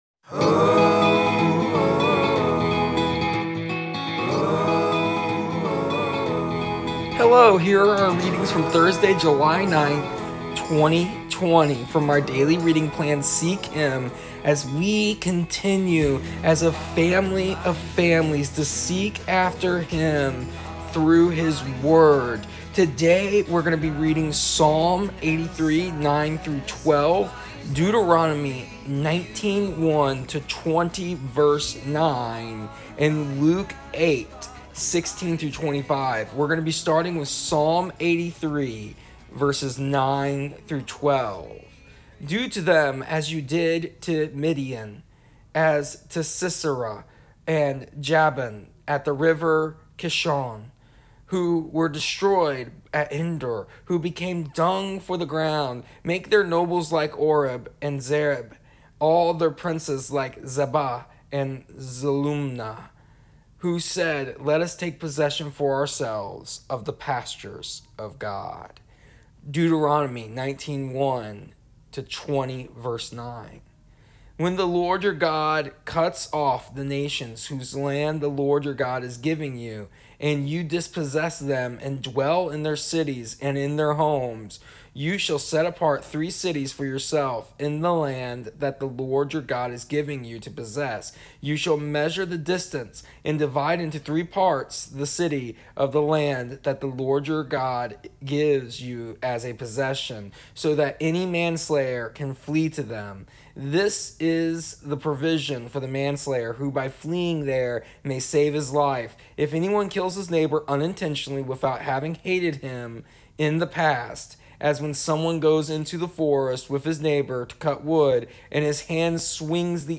Here is an audio version of our daily readings from our daily reading plan Seek Him for July 10th, 2020. After reading our Luke passage we ask a simple question.